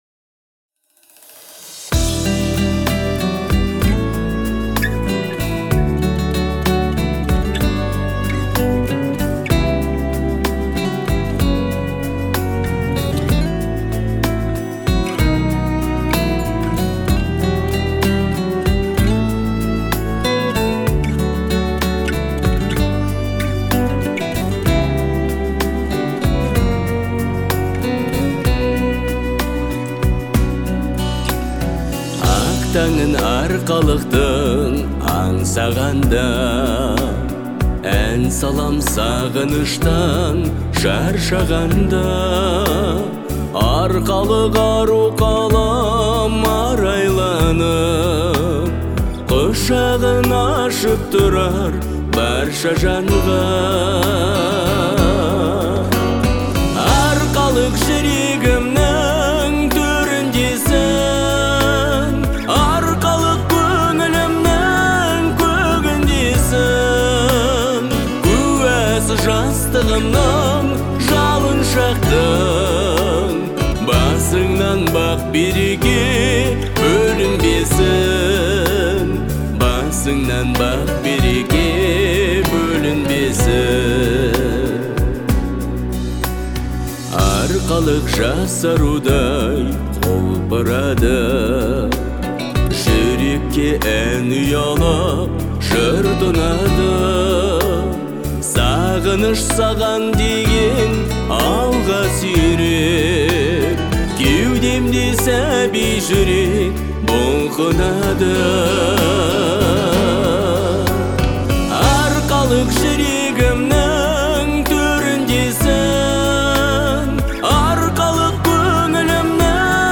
Казахские песни